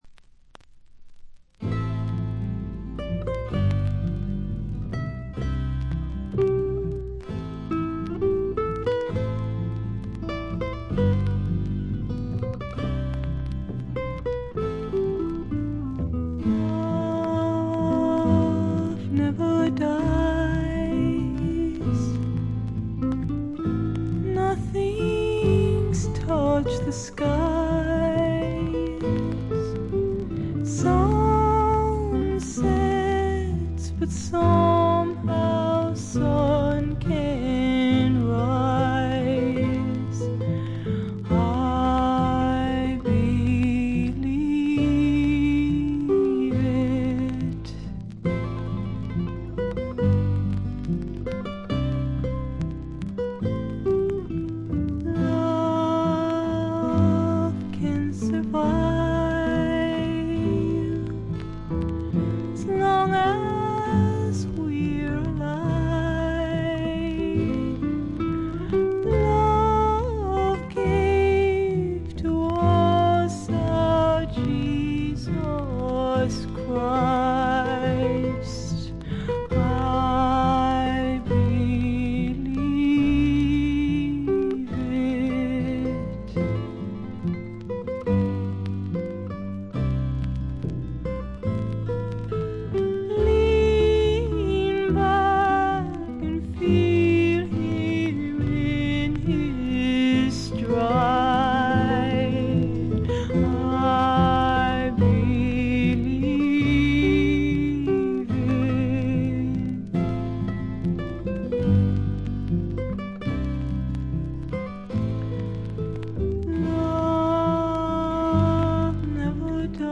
プレスがいまいちなのか、見た目よりバックグラウンドノイズやチリプチは多め大きめ。凶悪なものや周回ノイズはありません。
それを支えるシンプルなバックも見事！の一言。
試聴曲は現品からの取り込み音源です。